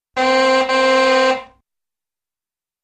Car Horn
Various Old Car Horns - Singles & Doubles - 8 Types ( Beeps, Honks, 'aaooga', Etc... )